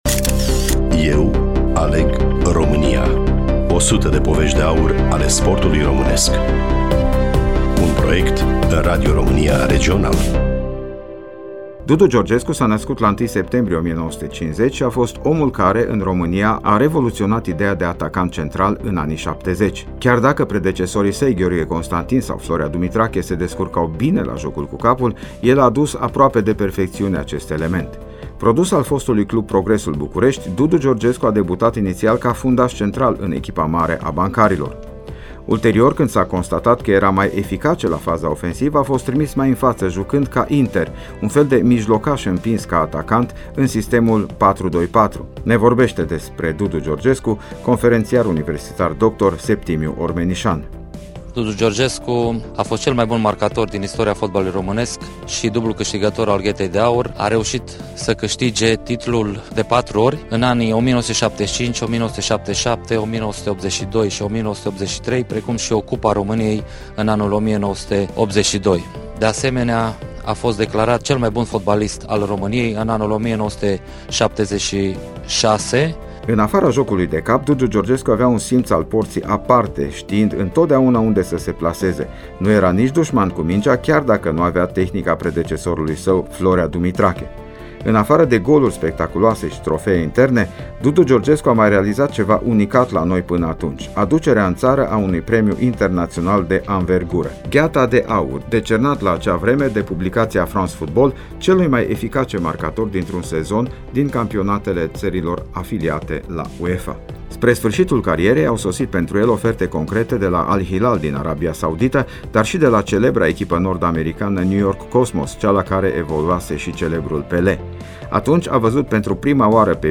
Studioul Regional Radio România Cluj